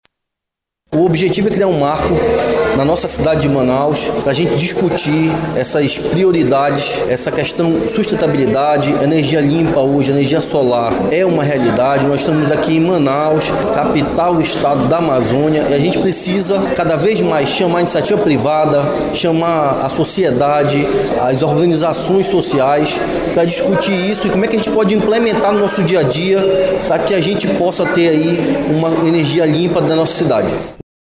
Sonora-1-Lissandro-Breval-–-vereador.mp3